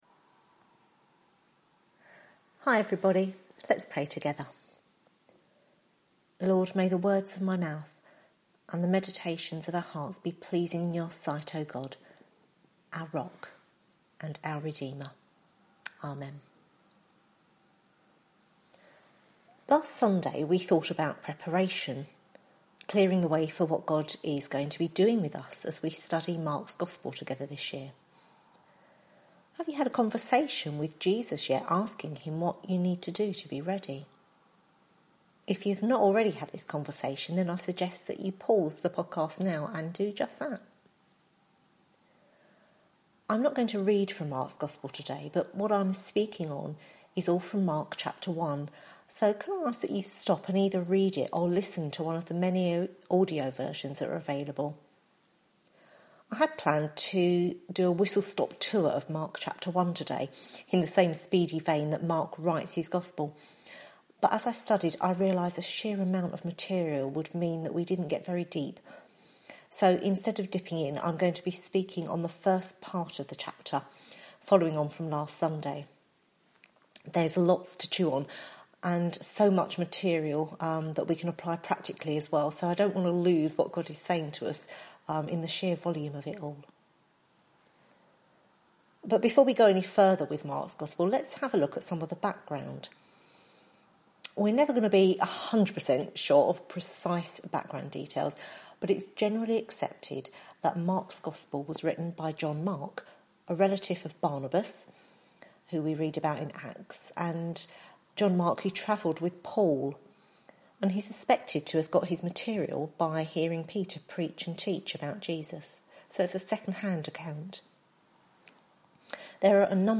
Mark Service Type: Sunday Morning Preacher